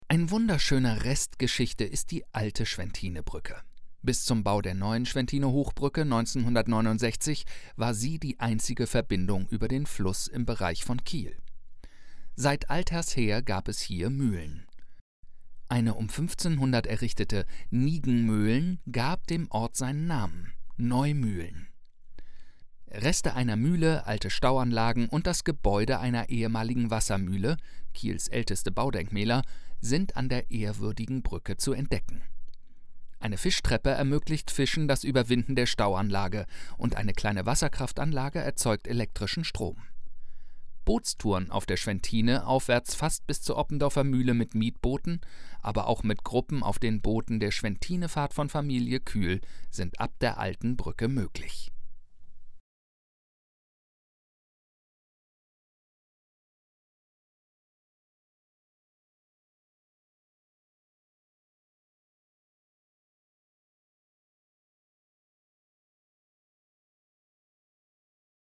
Audioversion dieses Textes